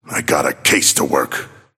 Abrams voice line - I got a case to work.